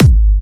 VEC3 Bassdrums Trance 37.wav